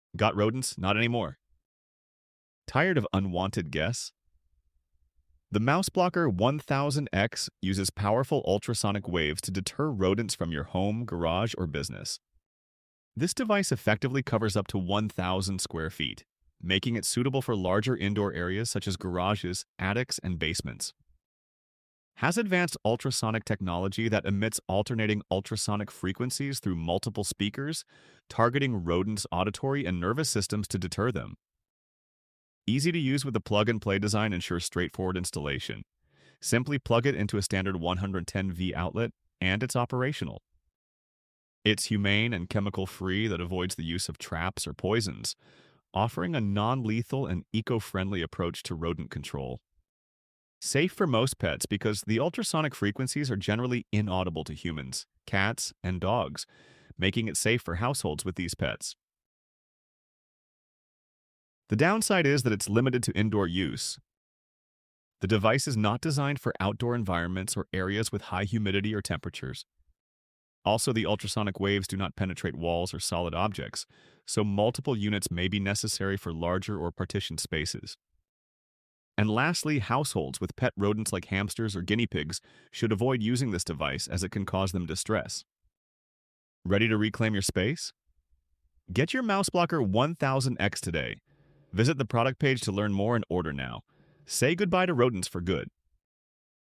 Mouseblocker pro1000x ultrasonic rodent Deterrent sound effects free download